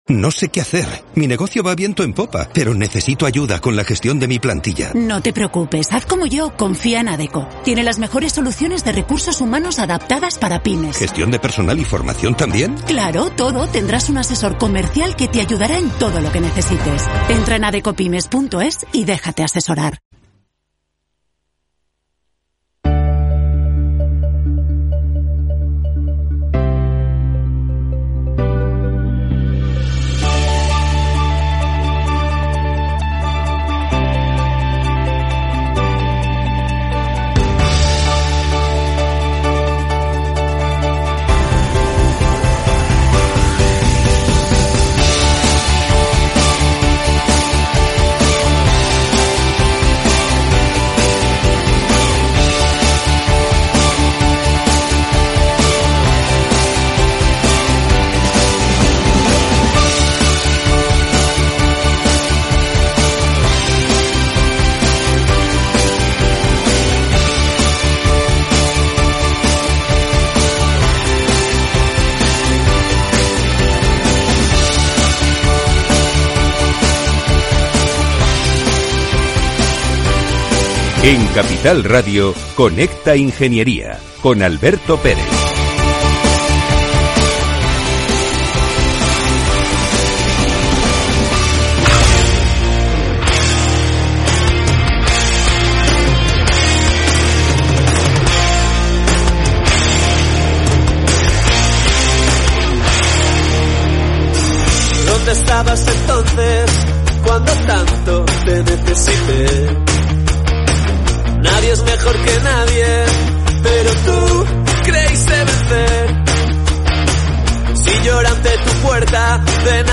Arrancamos temporada desde Santander38, donde asistimos en directo al Encuentro de la Economía Digital y las Telecomunicaciones.